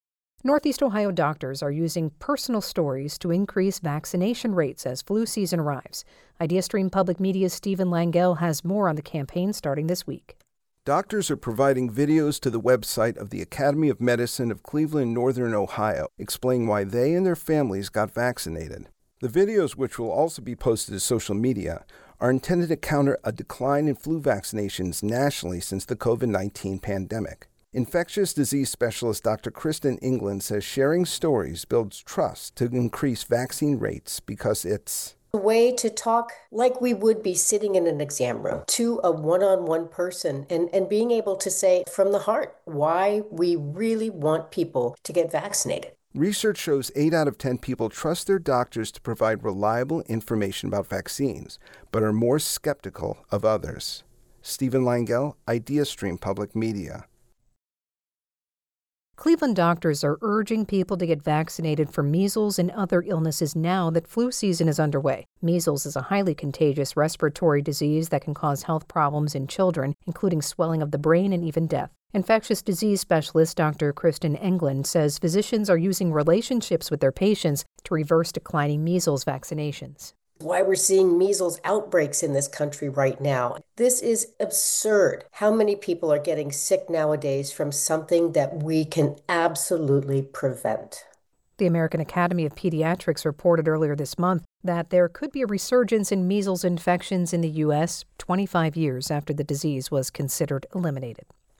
Vaccine campaign radio package.wav